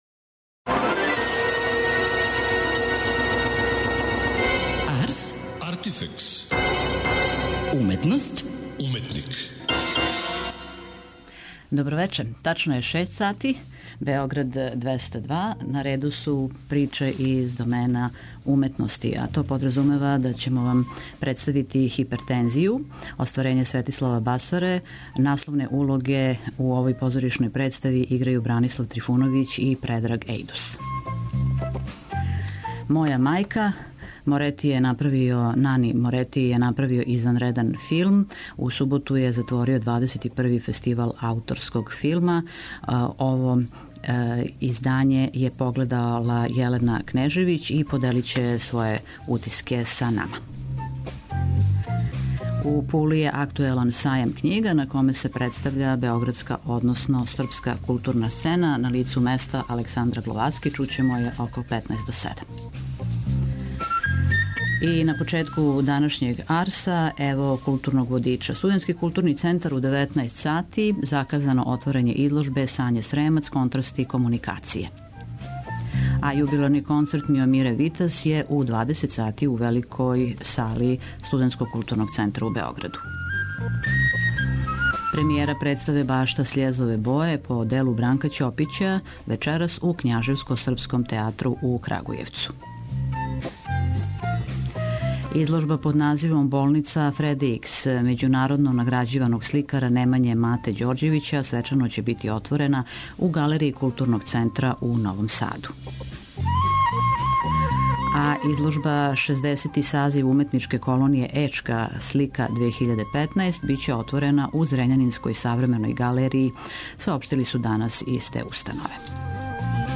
Ко је све у Пули, какви су програми, шта се дешава на Сајму и око њега, јављамо вам са лица места.